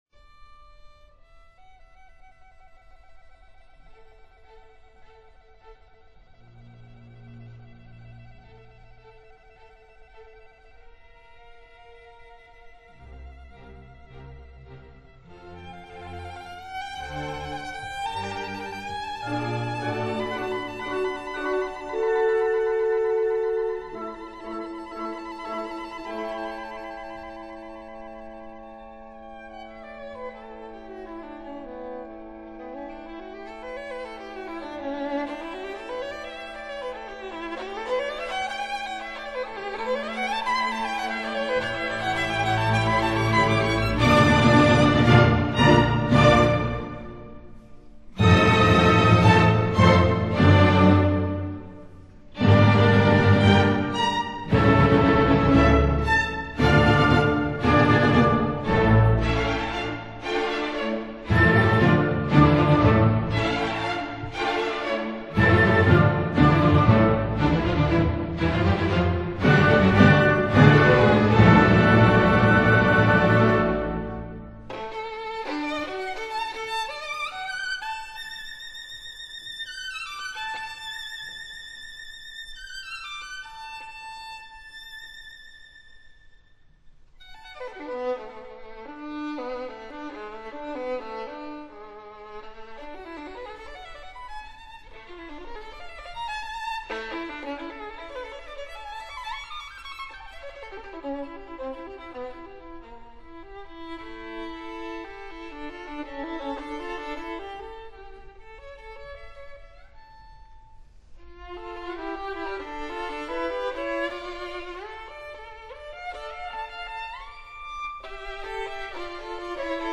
这首乐曲旋律柔美、格调高雅、规模宏大，颇具王者风范。
乐曲开始时，是定音鼓几下单独的敲击。
把定音鼓作为“独奏乐器”使用，这在当时是十分大胆和具有独创精神的。
鉴于论坛的速度暂时提供低品质的wma试听，请支持正版CD！